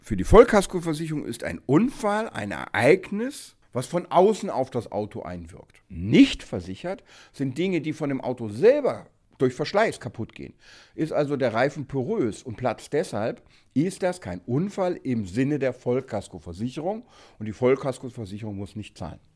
O-Ton: Reifenplatzer auf der Autobahn: Wann die Vollkasko zahlt – und wann nicht – Vorabs Medienproduktion